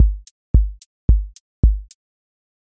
QA Test — four on floor
four on floor QA Listening Test house Template: four_on_floor April 17, 2026 ← Back to all listening tests Audio four on floor Open MP3 directly Selected Components macro_house_four_on_floor voice_kick_808 voice_hat_rimshot Test Notes What This Test Is Four on floor Selected Components macro_house_four_on_floor voice_kick_808 voice_hat_rimshot